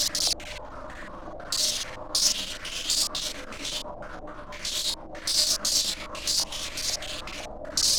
STK_MovingNoiseA-120_02.wav